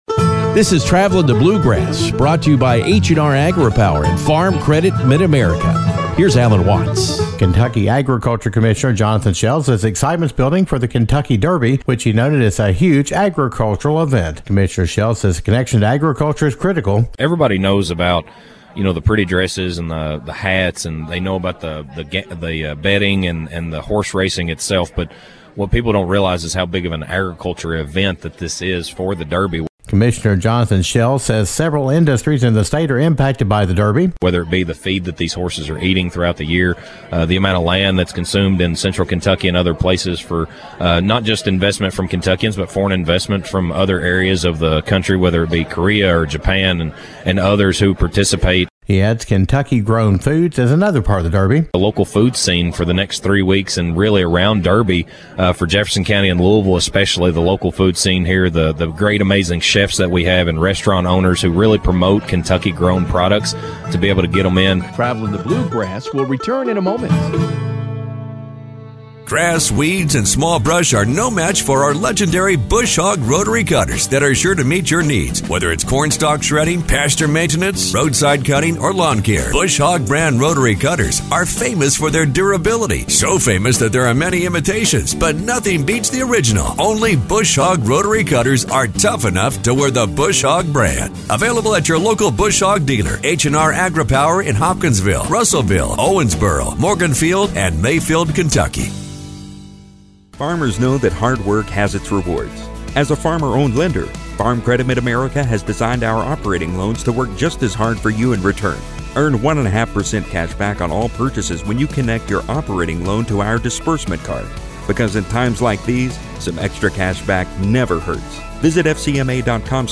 Kentucky Agriculture Commissioner Jonathon Shell says excitement is building for the Kentucky Derby and the events leading up to it. Shell describes why the derby is important to agriculture and the long-term impact.